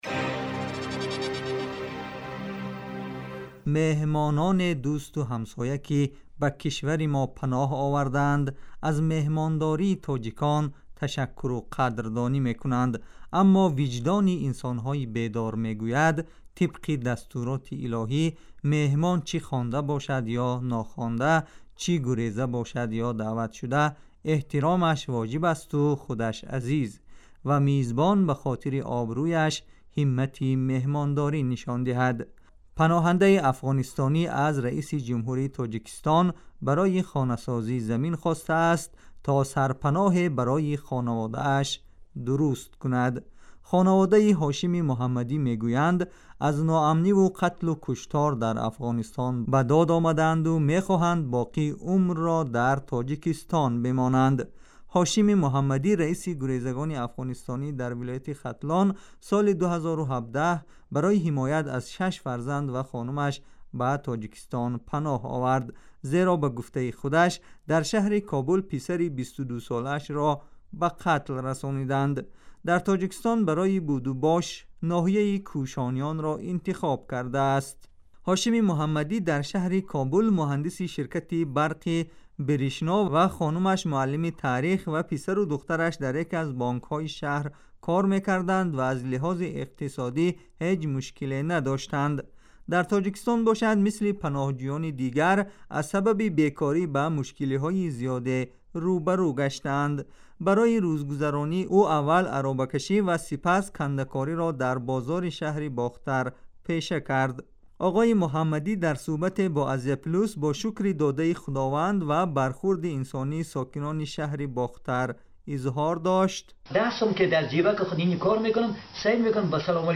گزارش ویژه : پناهنده افغانستانی؛ من عاشق تاجیکستانم